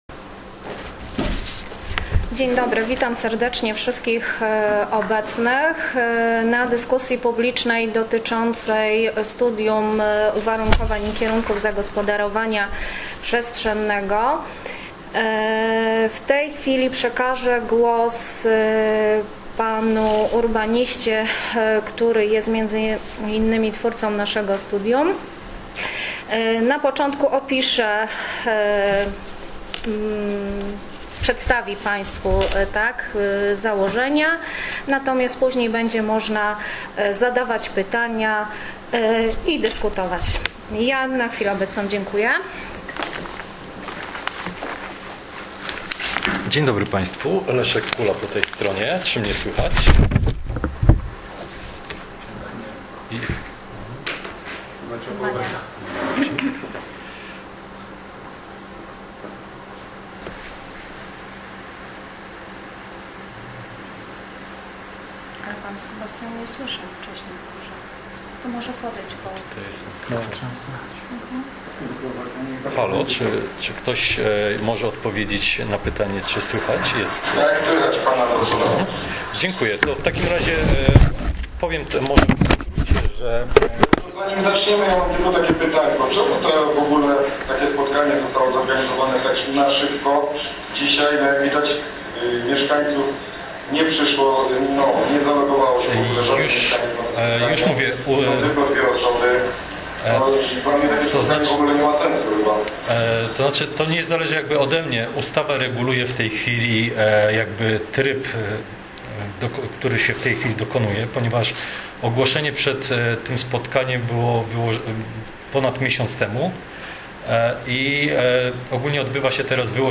W dniu 12 października 2020 r. odbyła się dyskusja publiczna nad rozwiązaniami przyjętymi w wyłożonych do wglądu dokumentach, tj. projekcie zmiany studium uwarunkowań i kierunków zagospodarowania przestrzennego Miasta Lędziny wraz z prognozą oddziaływania na środowisko.